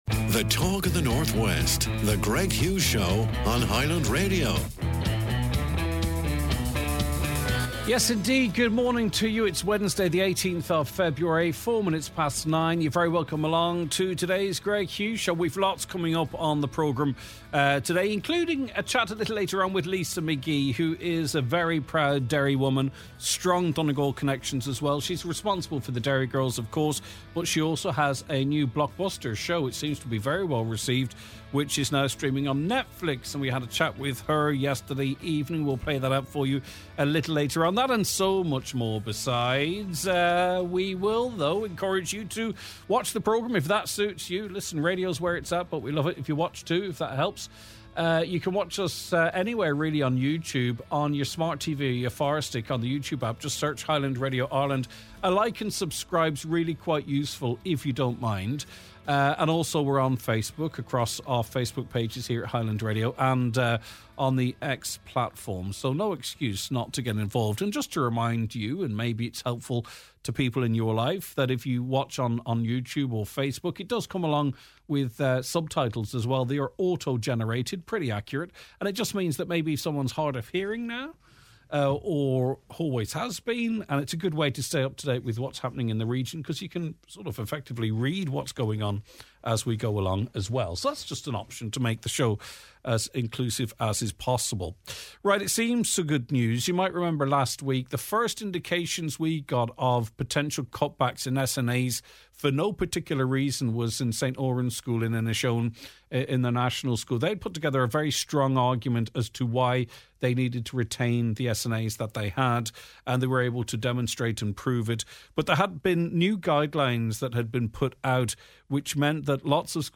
National No Smoking Day: We mark the day with a focus on quitting for good.